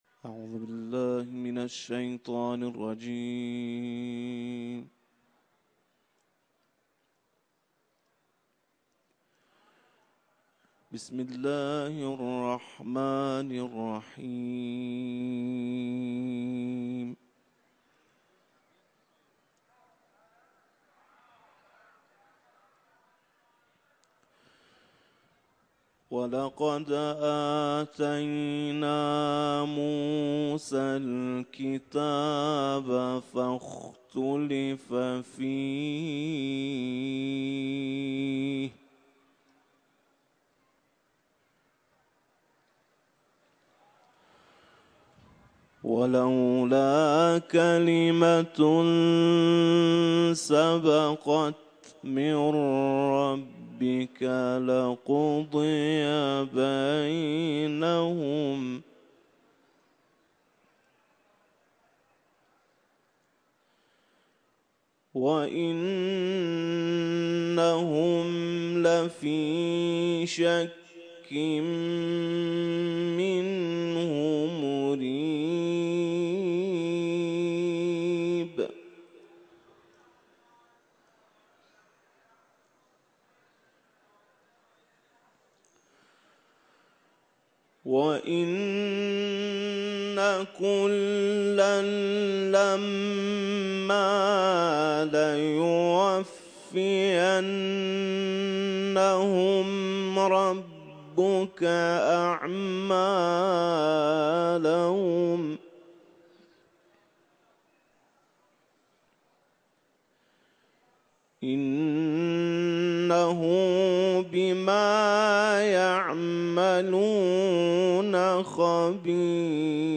IQNA – Uluslararası kâri İmam Rıza Türbesinde Hûd suresinden ayetler tilavet etti.